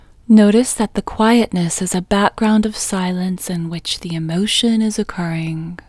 OUT Technique Female English 10